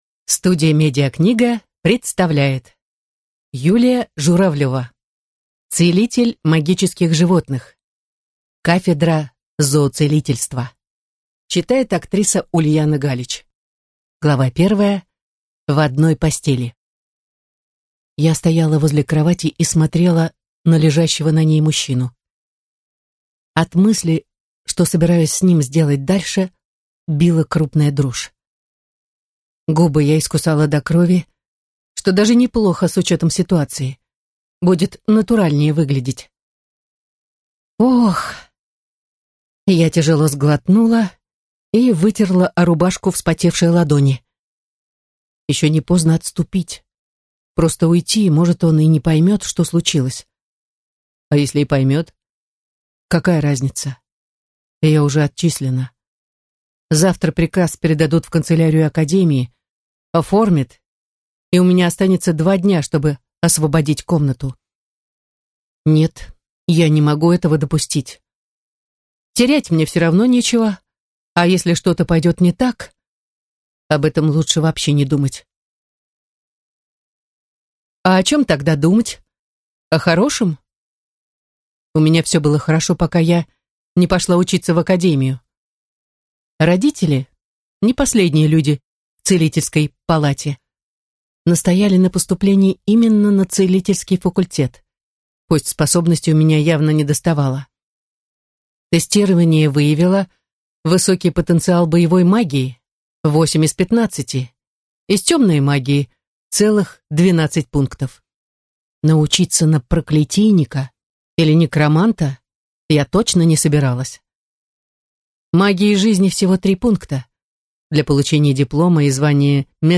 Аудиокнига Кафедра зооцелительства | Библиотека аудиокниг